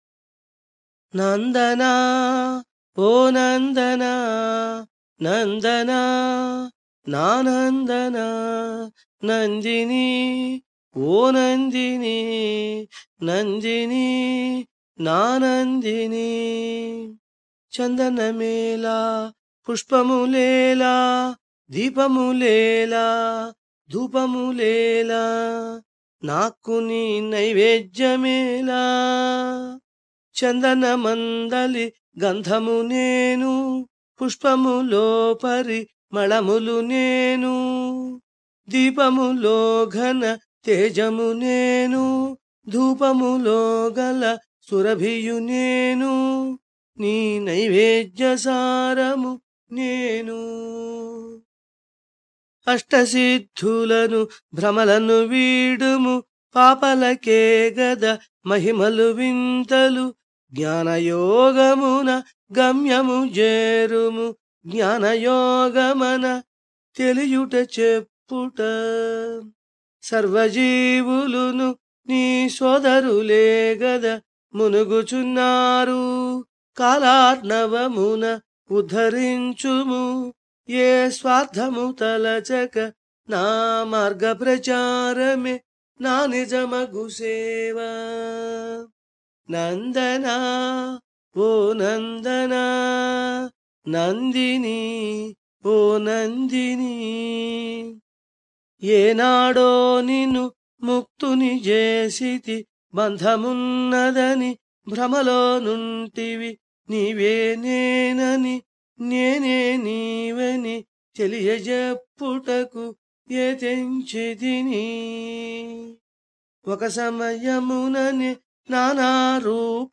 Free Download – Bhajans (MP3 Audio)